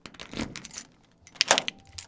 Keys in Deadbolt Lock Open Sound
household